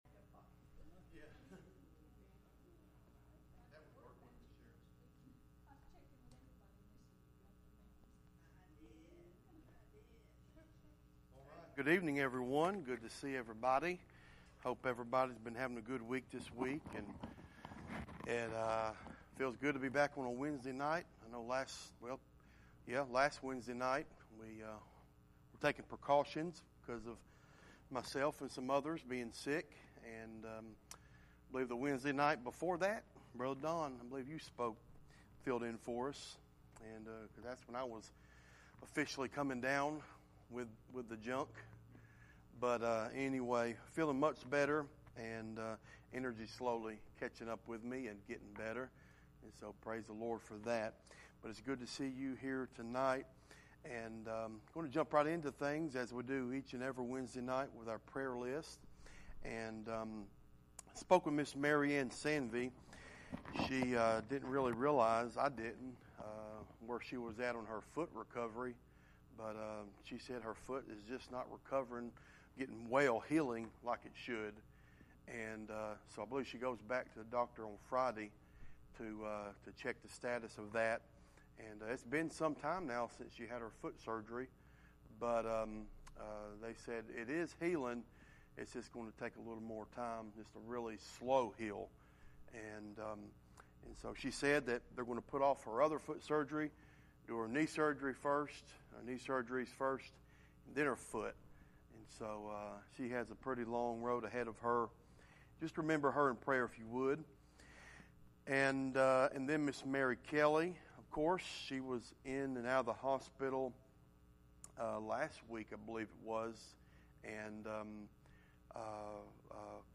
Service Type: Midweek Meeting